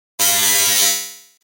دانلود آهنگ ربات 15 از افکت صوتی اشیاء
جلوه های صوتی
دانلود صدای ربات 15 از ساعد نیوز با لینک مستقیم و کیفیت بالا